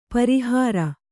♪ pari hāra